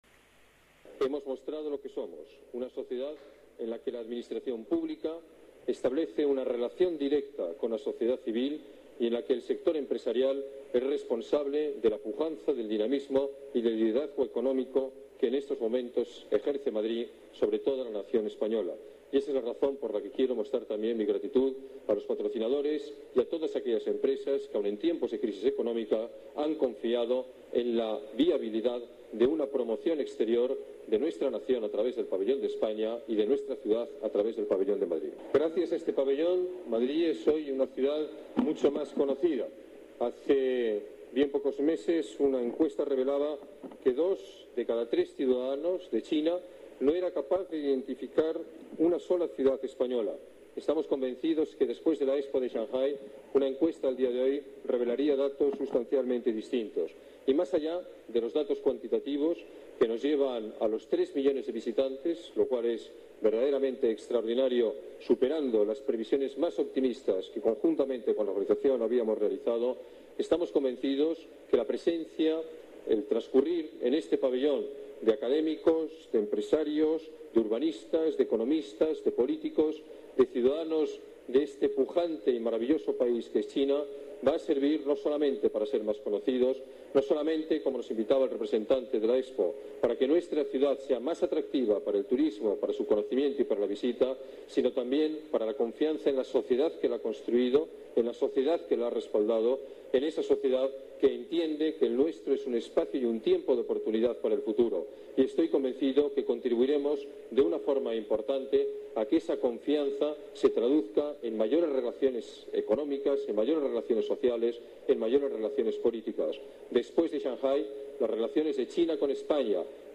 El alcalde de Madrid, Alberto Ruiz-Gallardón, ofrece una recepción en el Pabellón de Madrid de la Exposición Universal
Nueva ventana:Declaraciones del alcalde, Alberto Ruiz-Gallardón: encuentro entre culturas